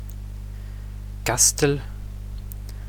la prononciation des mots!